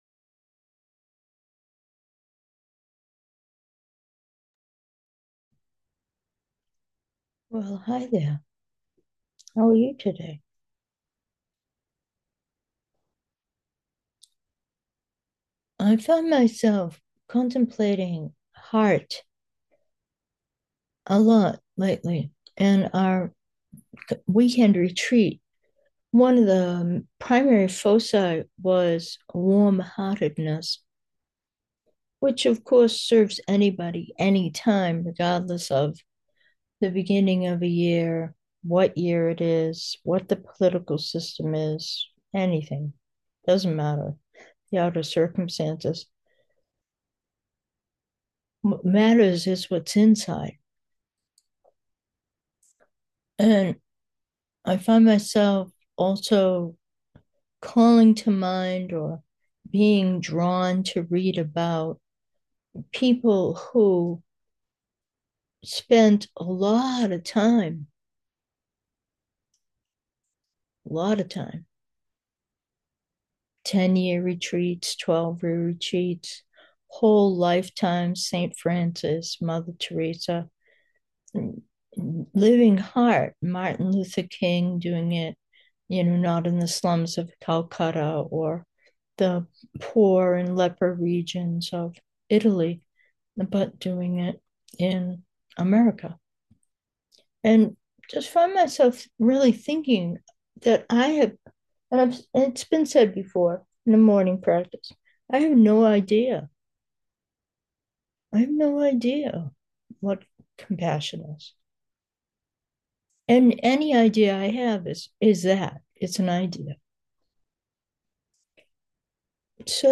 Meditation: exploring warm-heartedness 1